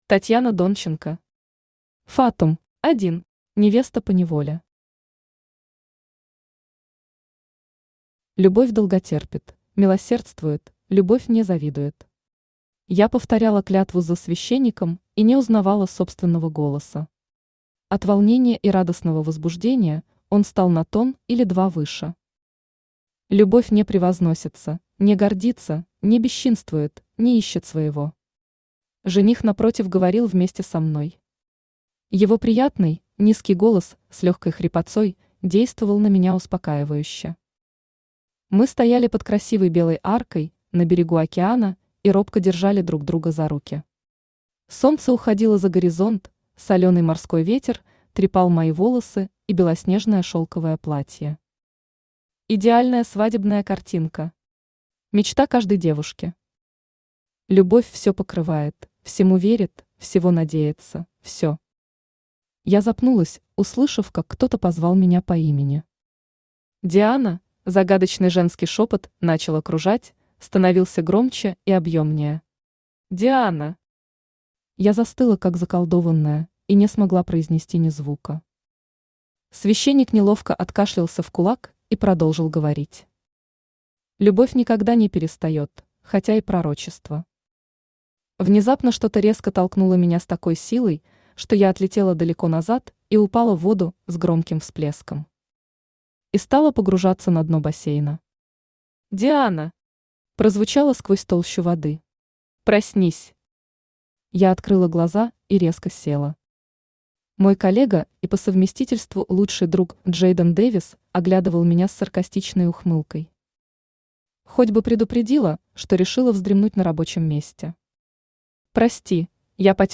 Aудиокнига Фатум Автор Татьяна Донченко Читает аудиокнигу Авточтец ЛитРес.